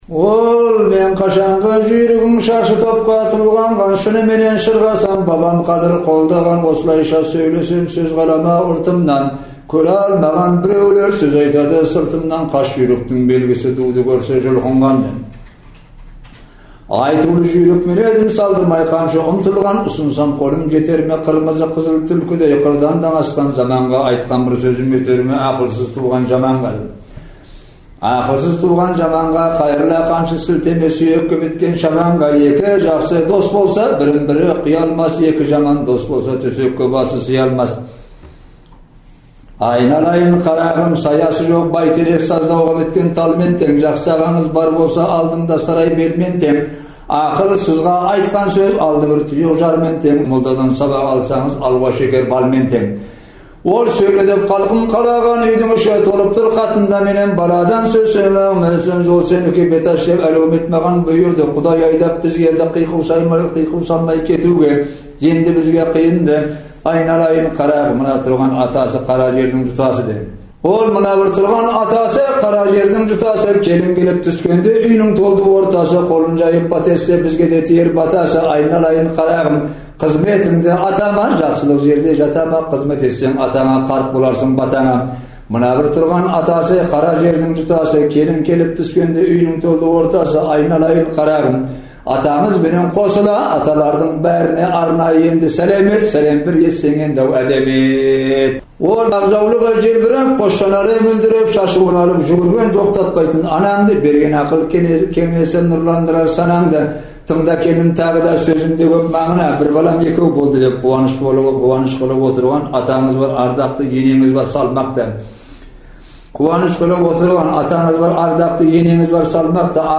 Иранда тұратын қазақ жыршысы